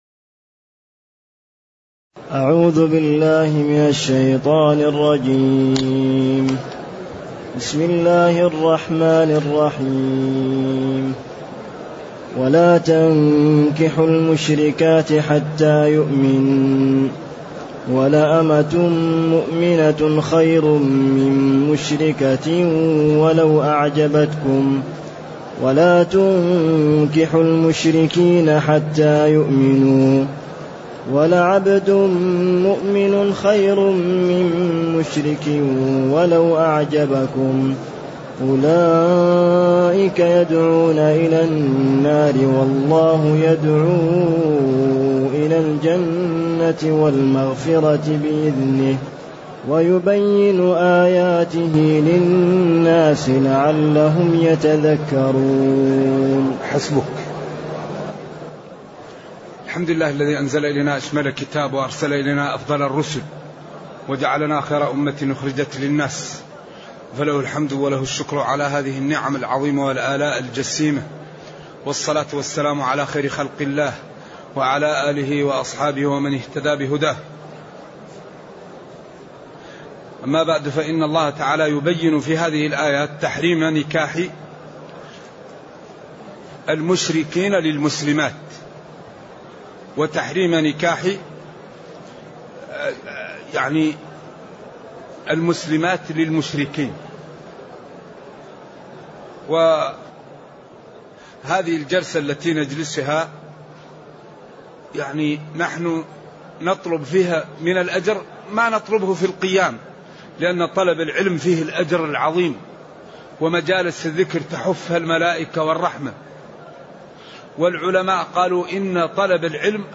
تاريخ النشر ٥ رمضان ١٤٢٨ هـ المكان: المسجد النبوي الشيخ